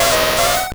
Cri de Ponyta dans Pokémon Or et Argent.